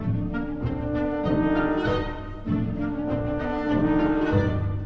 Version choisie pour les extraits : prestation de Anna Vinnitskaya et l'Orchestre National de Belgique sous la direction de Gilbert Varga lors de la finale du Concours Reine Elisabeth et avec lequel elle remporta le 1er Prix en 2007.
Mes. 11 env. 0'32''. L'humour se pointe par la formule descendante des clarinettes à laquelle répond le hautbois, sur la formule cadentielle implacable